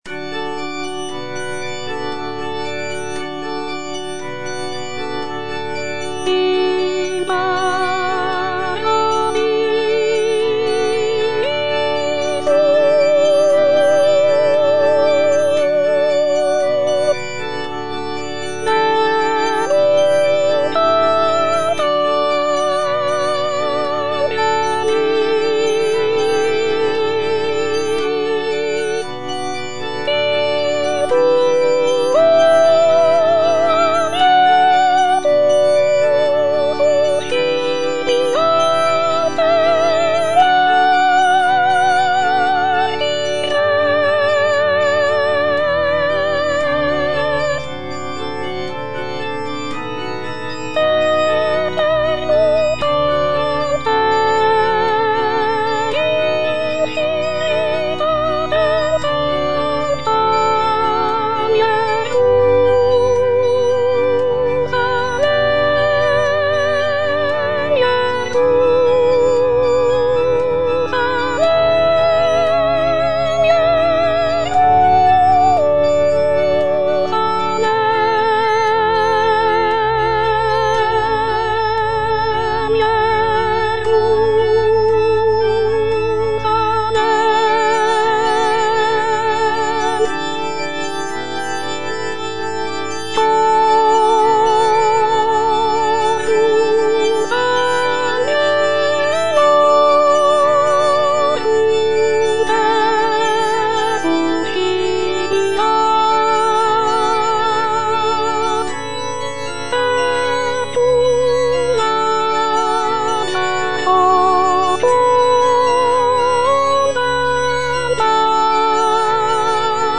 version with a smaller orchestra
Soprano (Voice with metronome) Ads stop